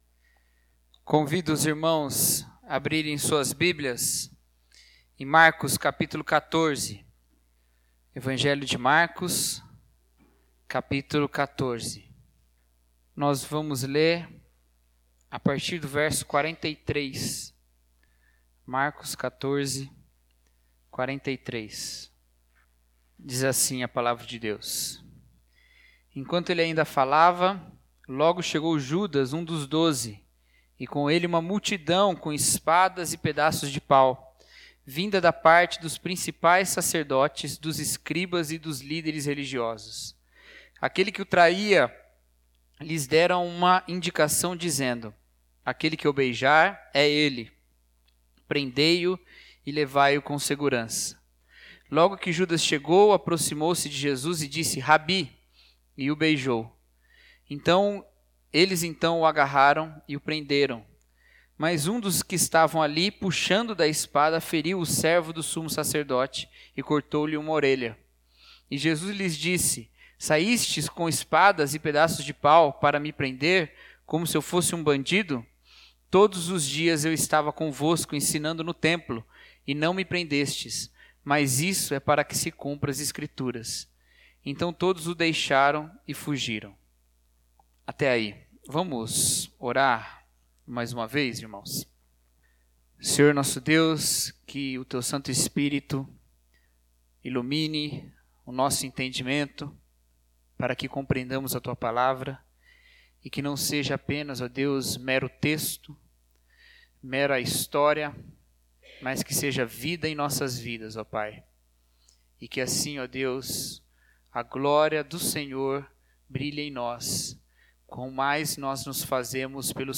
Mensagem: O Sacrifício Solitário